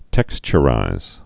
(tĕkschə-rīz)